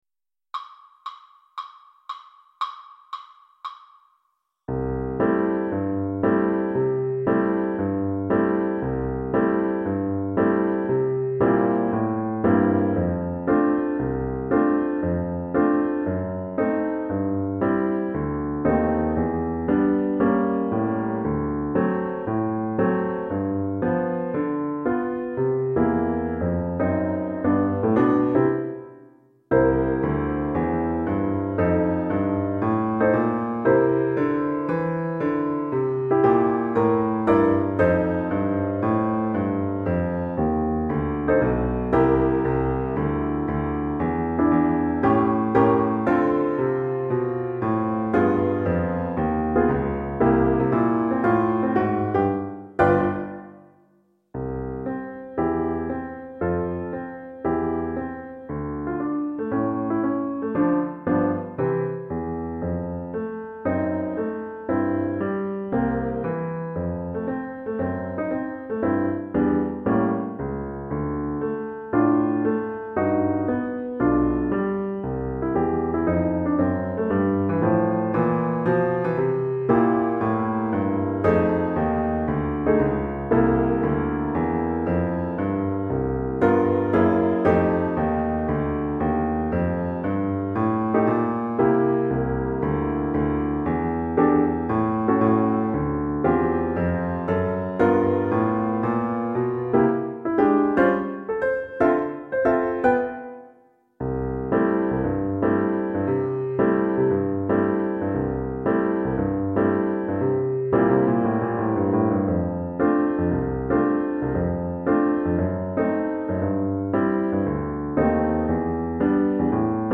Swinging Shepherd Blues piano part